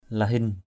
/la-hɪn/